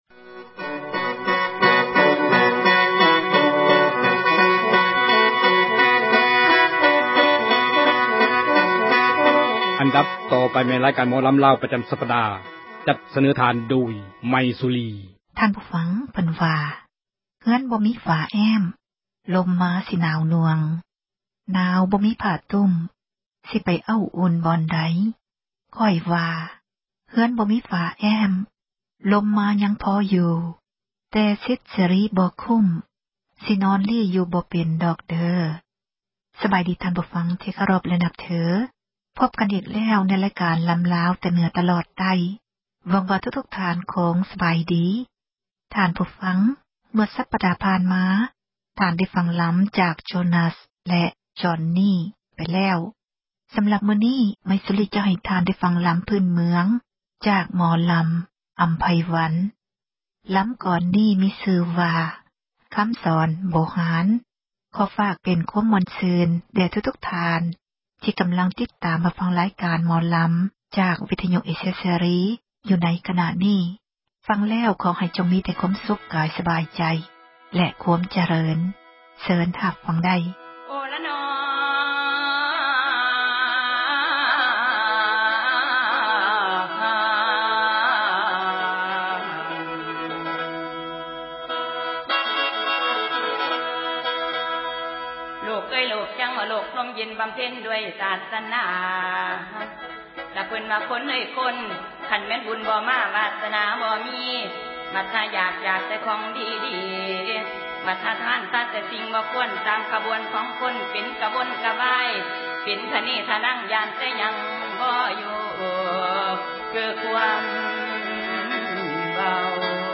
ຣາຍການ ໝໍລໍາ